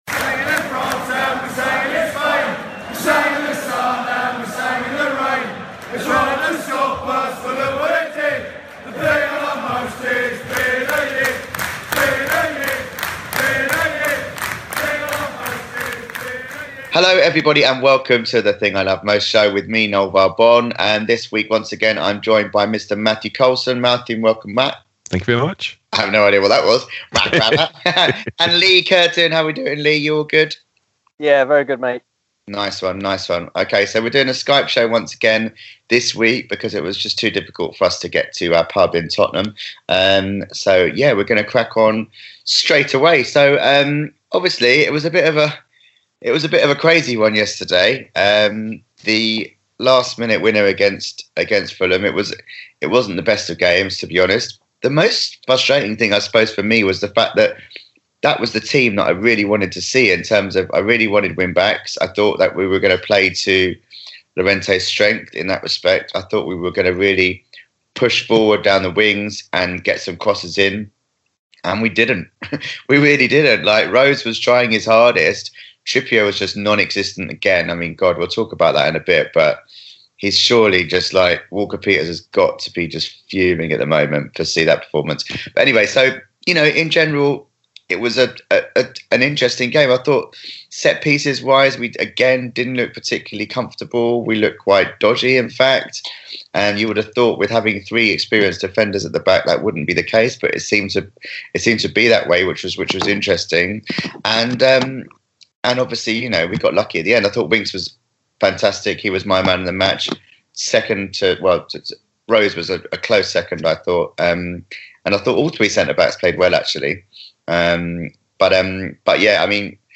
In this week's Skype show